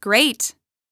8 bits Elements
Voices Expressions Demo
Great_2.wav